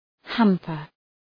Προφορά
{‘hæmpər}